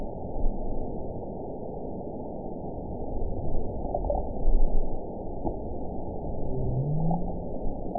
event 917169 date 03/22/23 time 23:34:22 GMT (2 years, 1 month ago) score 9.66 location TSS-AB03 detected by nrw target species NRW annotations +NRW Spectrogram: Frequency (kHz) vs. Time (s) audio not available .wav